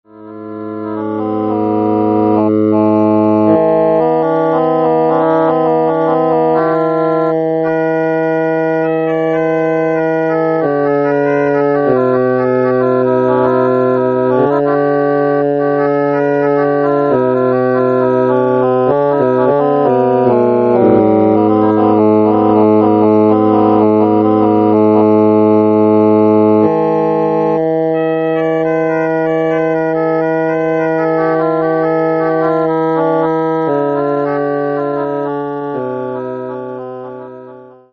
1.〜3. で若干の作曲がなされている他は全て完全即興である。
キーを選ぶようなたどたどしいタッチは、このような瞬間的な創作の連続の結果といえる。
演奏自体は全曲一発録りである。